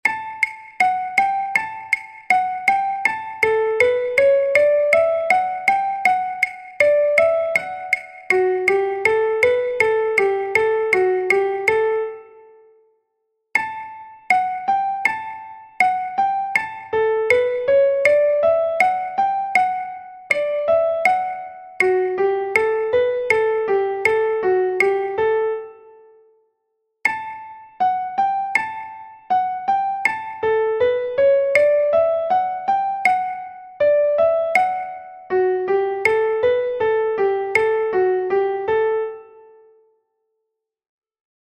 Im folgenden Beispiel hörst du die Melodie vom Kanon in D mit verschiedenen Metronom-Einstellungen. Es fängt an mit Metronom auf 16el eingestellt (Tempo 160 BPM), dann auf Achtel (Tempo halbiert auf 80 BPM), dann auf Viertel (Tempo nochmal halbiert auf 40 BPM).
kanonDmetronom.mp3